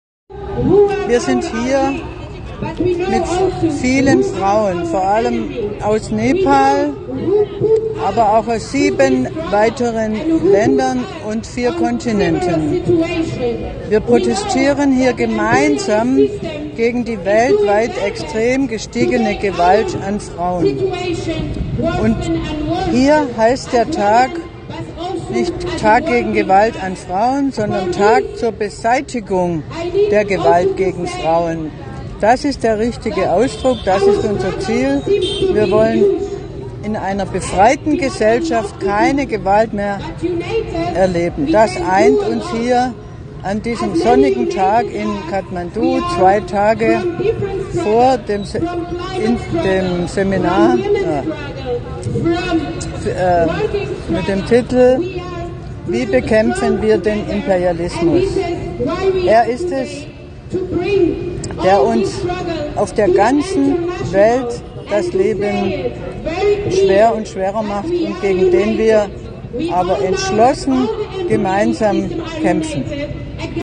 In Kathmandu auf der Straße zum Internationalen Tag für die Beseitigung der Gewalt an Frauen